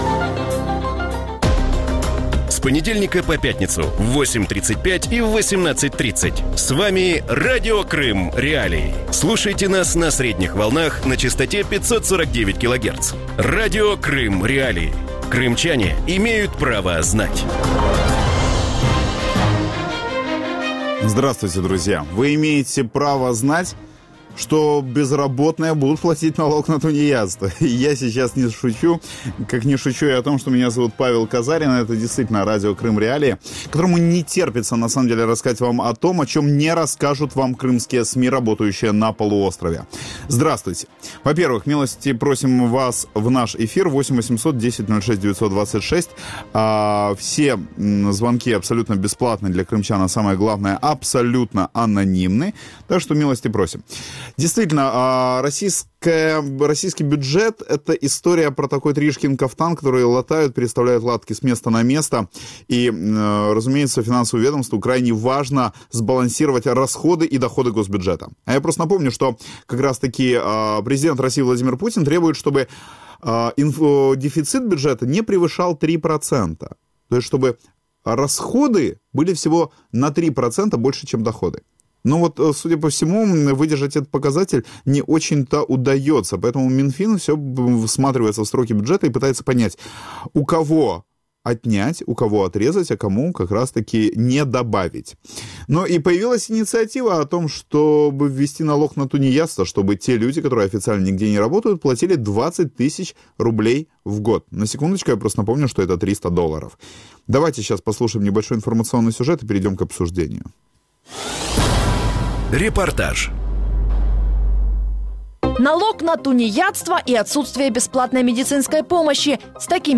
У вечірньому ефірі Радіо Крим.Реалії обговорюють пропозицію російських чиновників позбавити безробітних безкоштовної медицини і стягувати з них «податок на дармоїдство». Як вдарять ці ініціативи по кримчанам без офіційного працевлаштування, чи знизиться рівень безробіття у Криму і чи почне кримський бізнес виходити з «тіні»?